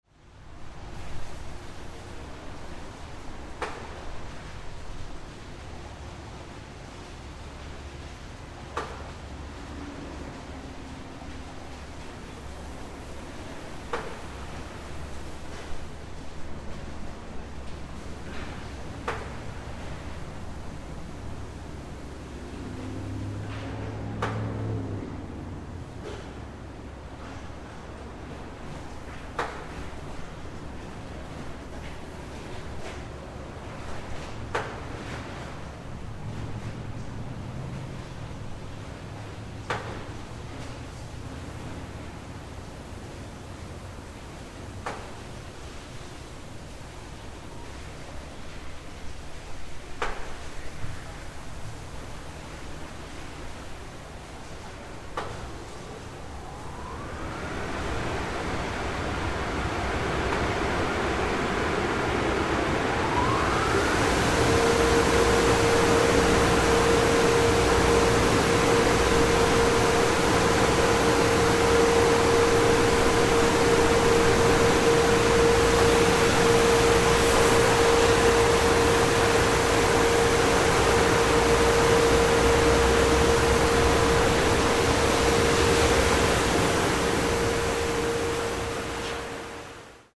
Шум пылесоса при чистке салона на автомойке